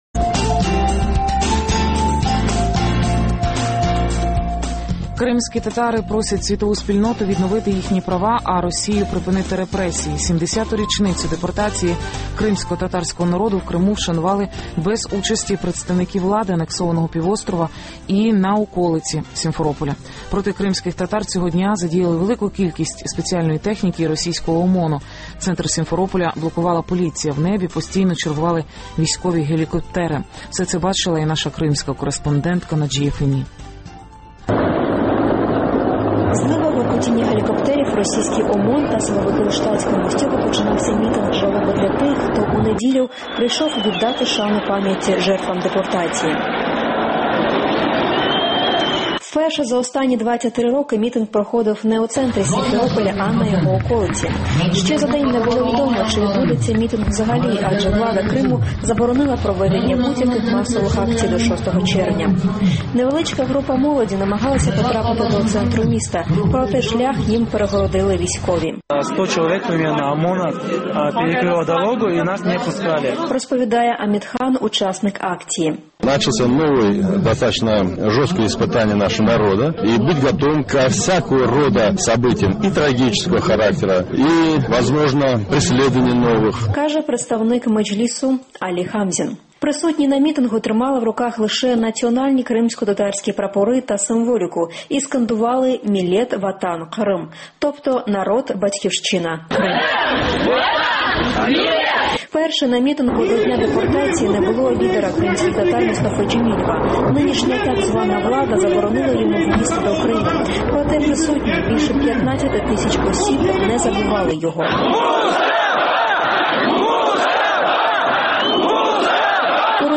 Мітинг жалоби в Криму: під гуркіт гелікоптерів і без Мустафи Джемілєва